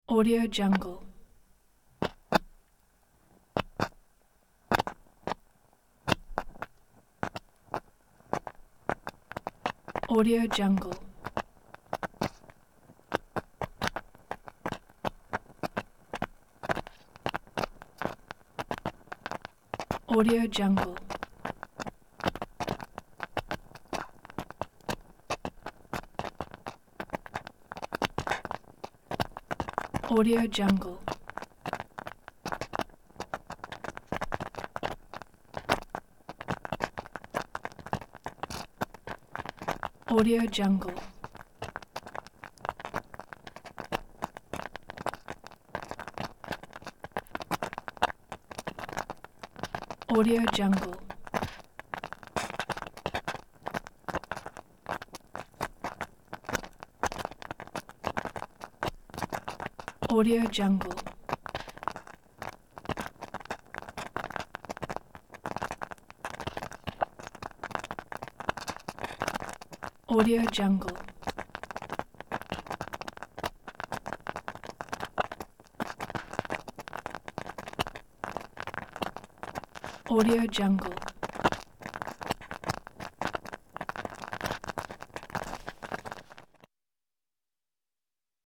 صدای قل قل کردن سوپ جو در حال پختن، نویدبخش گرمای دلچسب و طعمی دلنشین است.
Sample rate 16-Bit Stereo, 44.1 kHz
Looped No